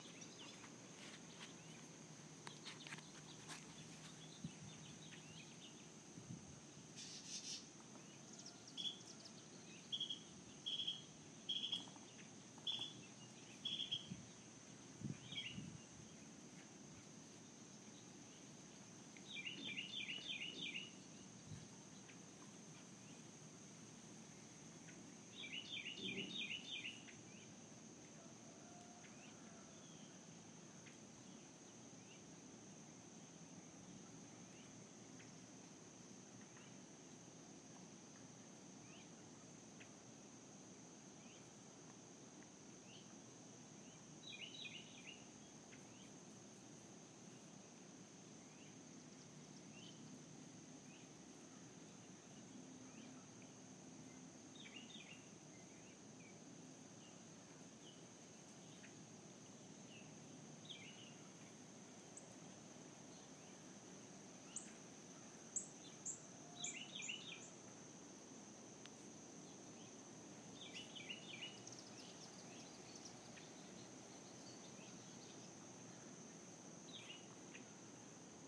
More birds